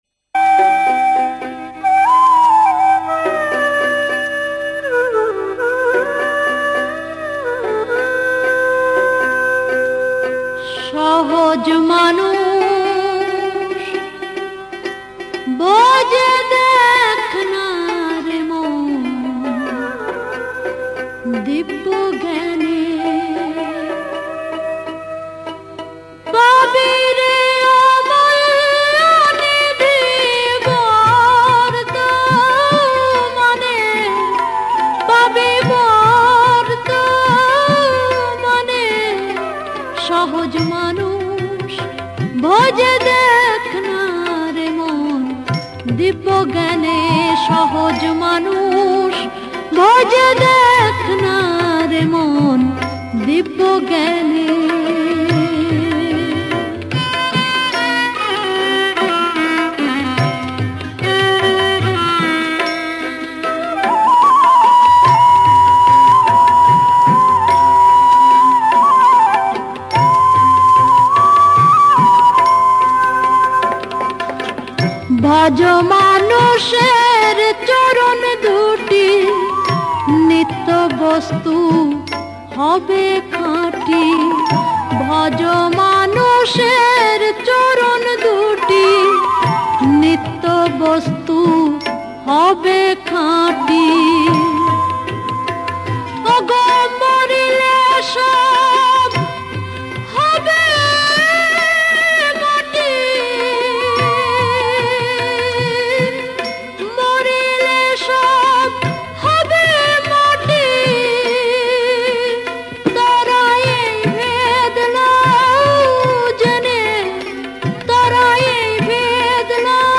Bengali Kalam